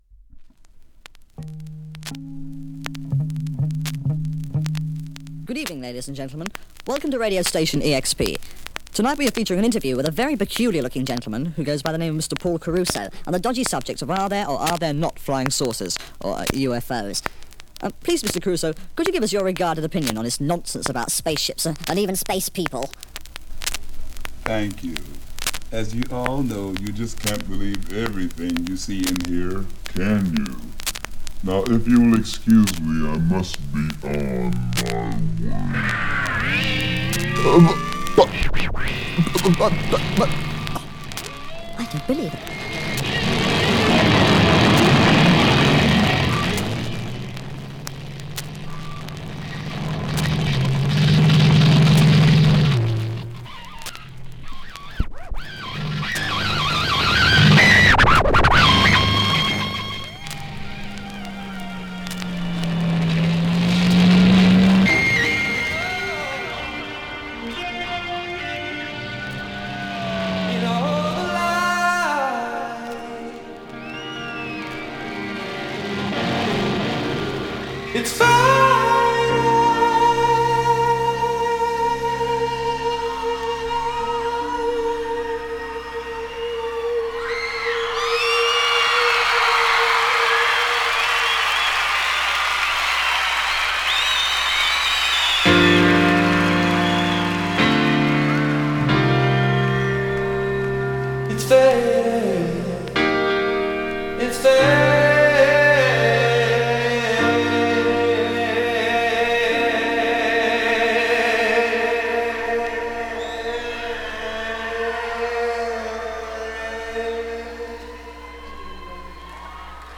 Recorded live at the Montreal Forum in March 1983